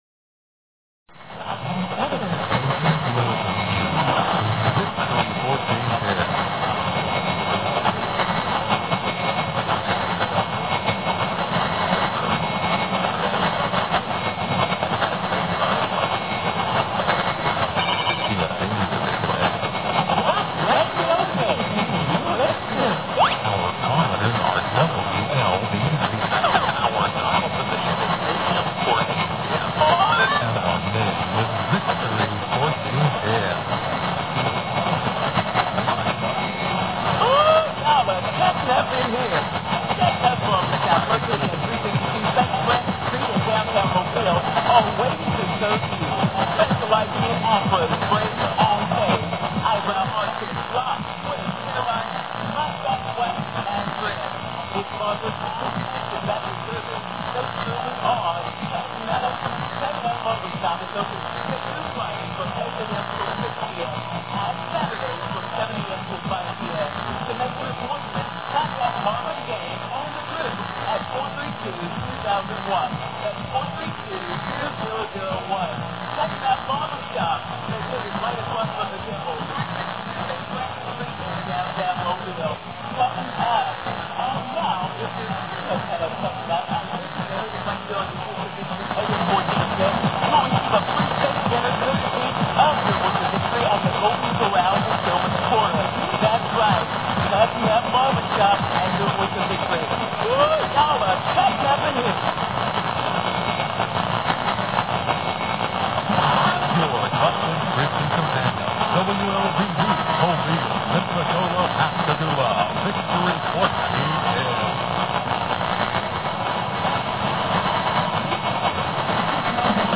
Heard at 9:30PM. fades in nice and clearly OVER WIZM from Madison, WI during an aurora!